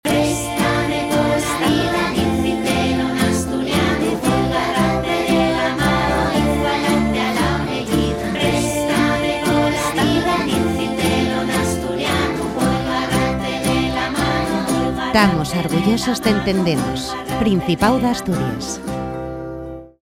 SPOT / CUÑA RADIO /